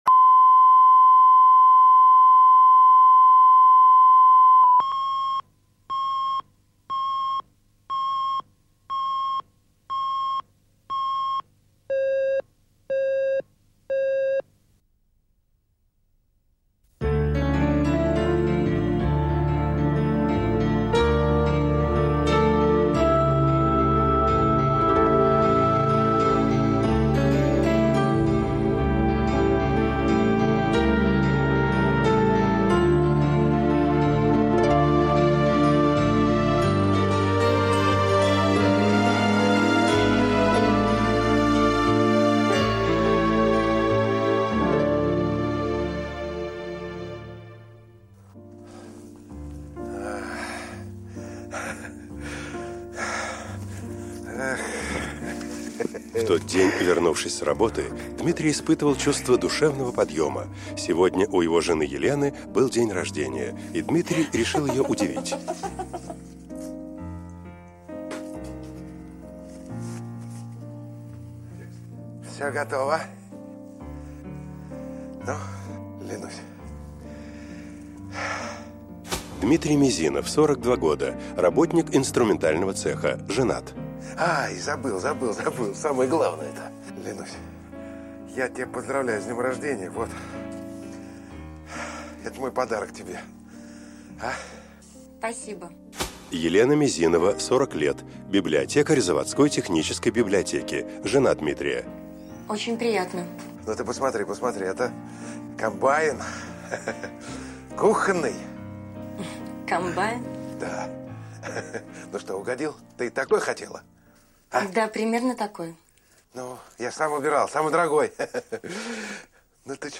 Аудиокнига Не пара | Библиотека аудиокниг
Прослушать и бесплатно скачать фрагмент аудиокниги